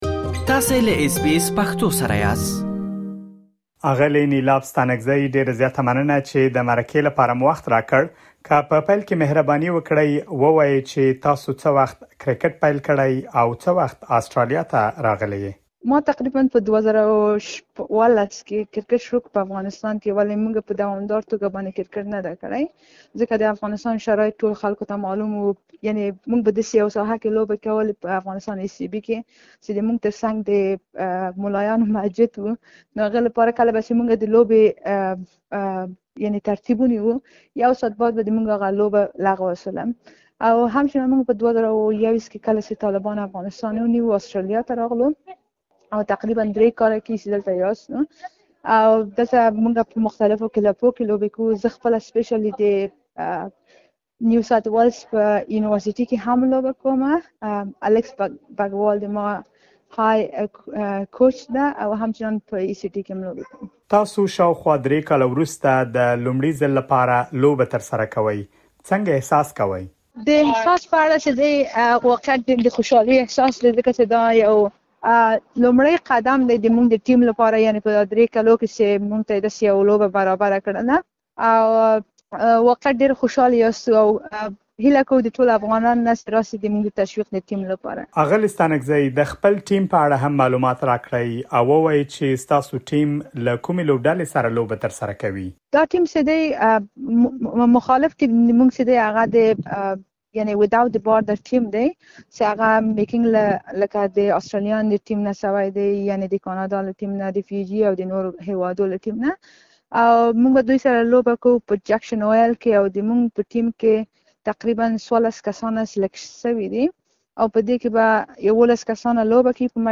تاسو کولی شئ لا ډېر معلومات په ترسره شوې مرکې کې واورئ.